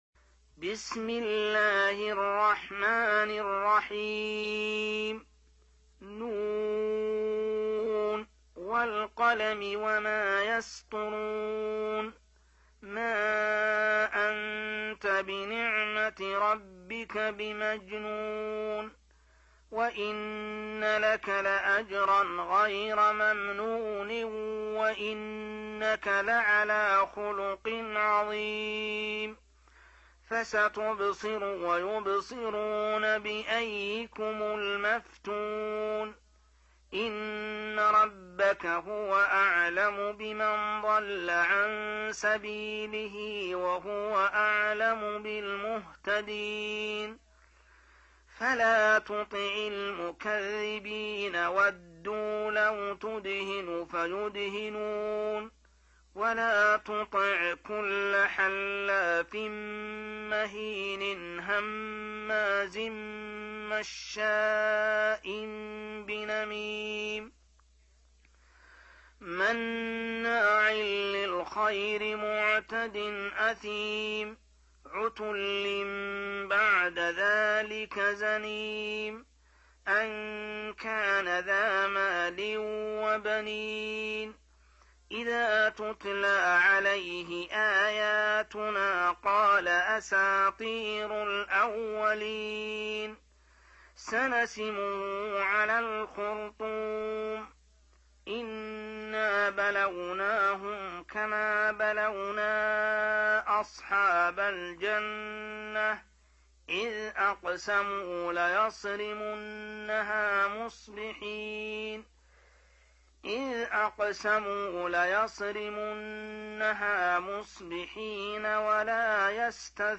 68. Surah Al-Qalam سورة القلم Audio Quran Tarteel Recitation
Surah Sequence تتابع السورة Download Surah حمّل السورة Reciting Murattalah Audio for 68. Surah Al-Qalam سورة القلم N.B *Surah Includes Al-Basmalah Reciters Sequents تتابع التلاوات Reciters Repeats تكرار التلاوات